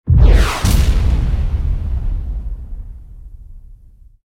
ogg / ships / combat / missile1.ogg
missile1.ogg